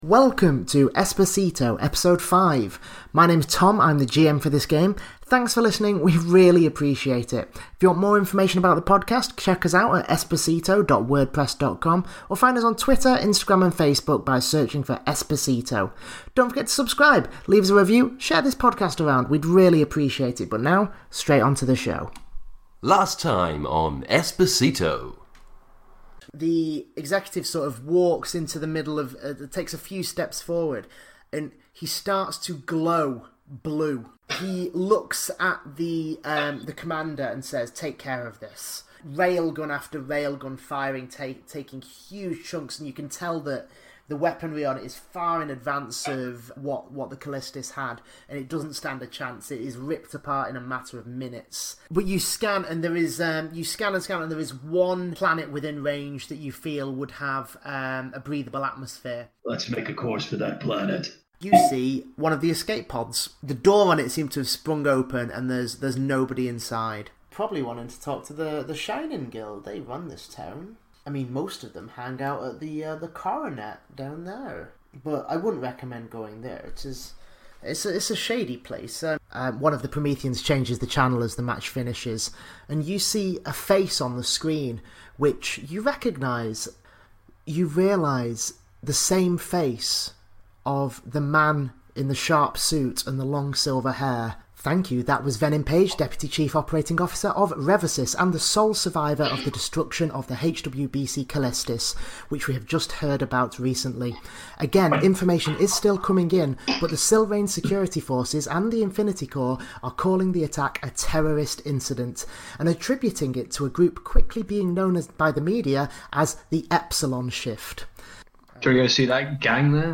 A podcast of an Esper Genesis game, a science fiction table top role play game compatible with Dungeons and Dragons 5th Edition.